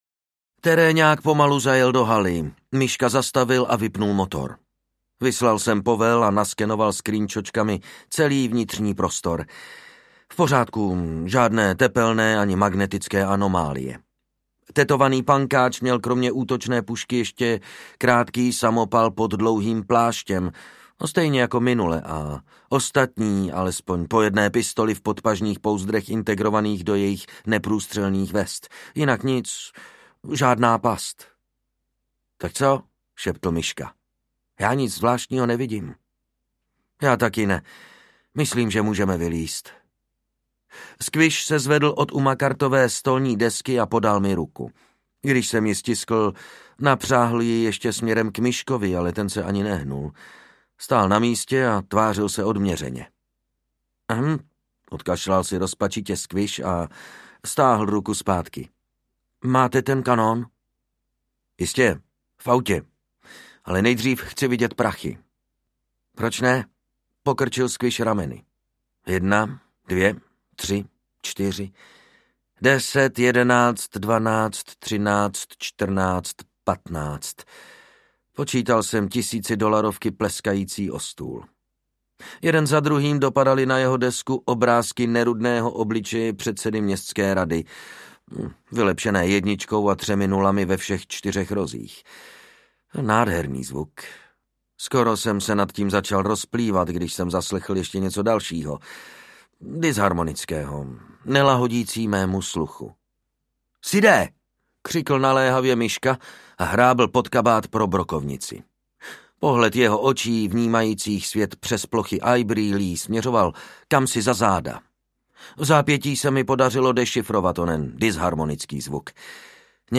Slzy zítřka audiokniha
Ukázka z knihy
Vyrobilo studio Soundguru.